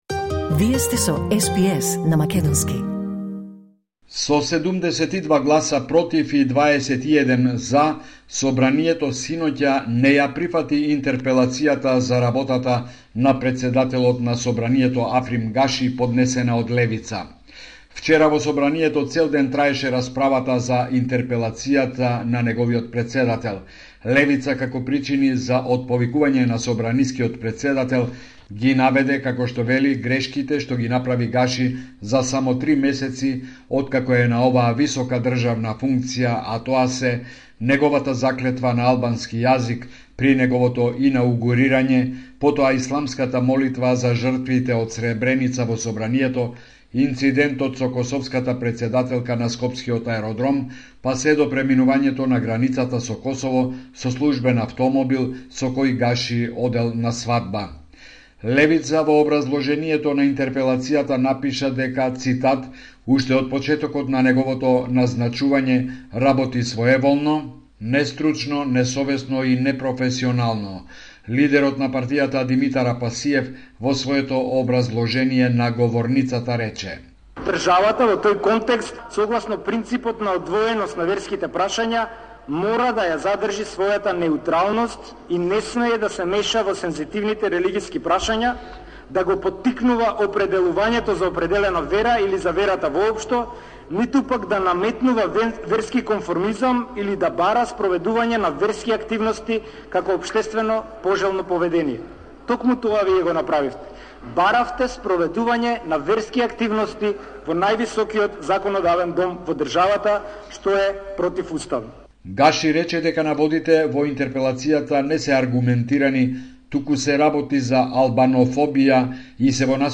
Извештај од Македонија 4 септември 2024